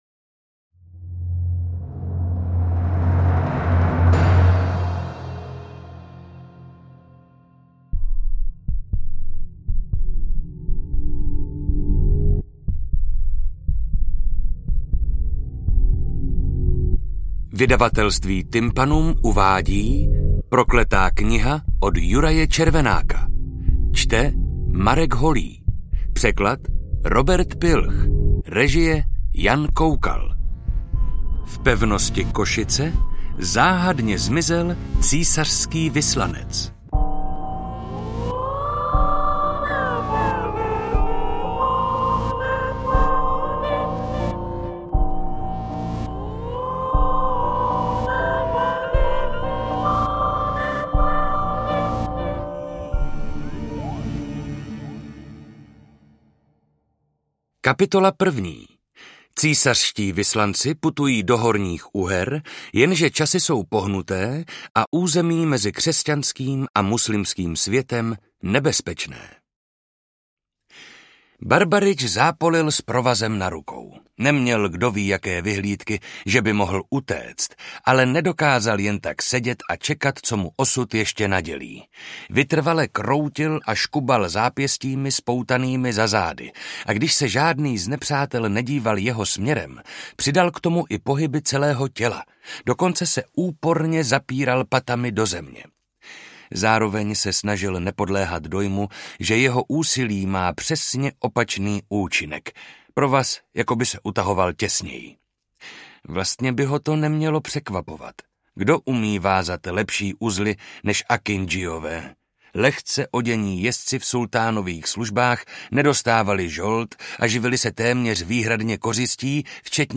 Interpret:  Marek Holý